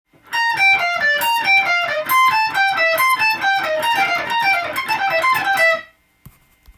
譜面通り弾いてみました
ペンタトニックスケールを繰り返しているだけですが